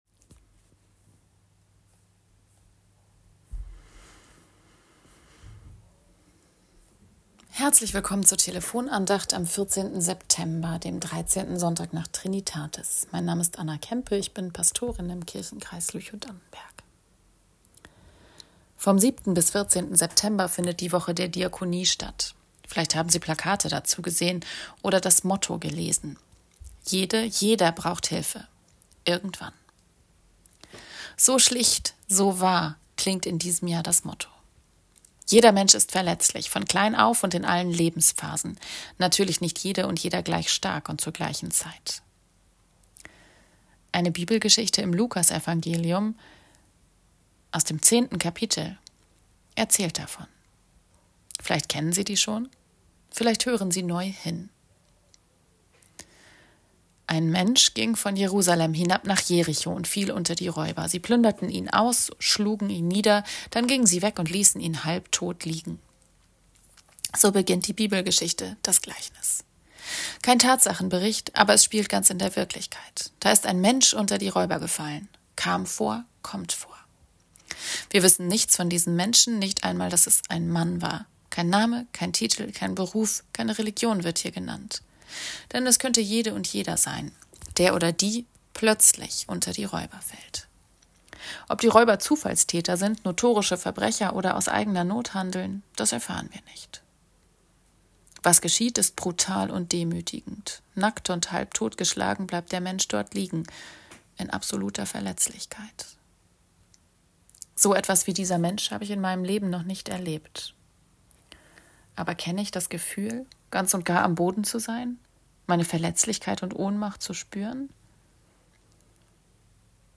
~ Telefon-Andachten des ev.-luth.